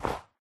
Sound / Minecraft / dig / snow3